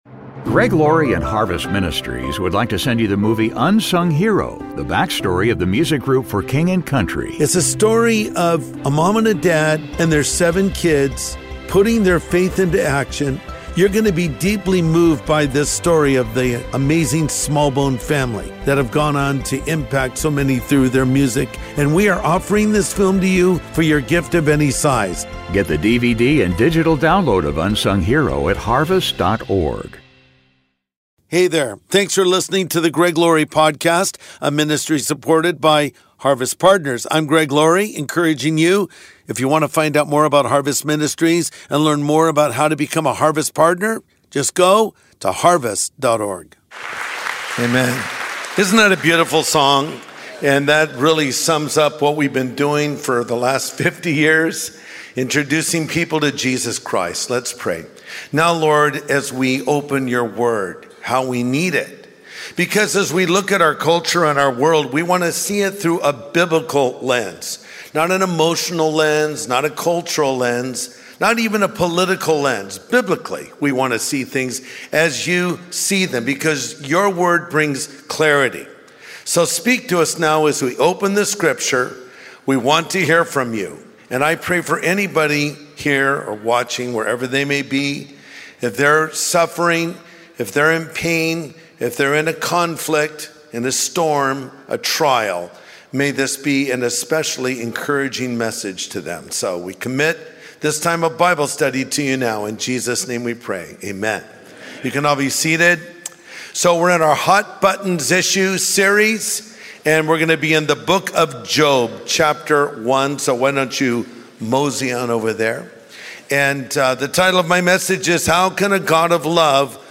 Pastor Greg Laurie responds to our question of why God allows suffering.